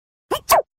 Cartoon Sneeze Sound Effect Free Download
Cartoon Sneeze